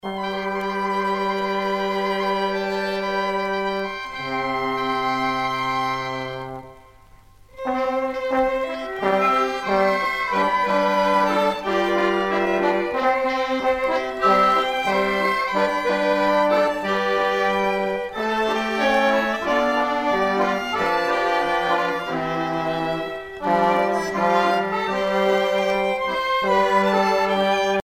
Usage d'après l'analyste gestuel : danse
Catégorie Pièce musicale éditée